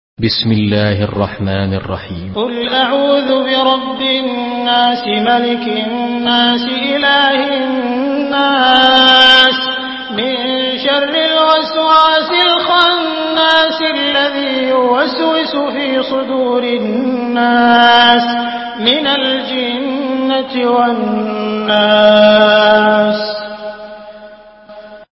Surah আন-নাস MP3 in the Voice of Abdul Rahman Al Sudais in Hafs Narration
Murattal Hafs An Asim